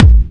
KICK161.wav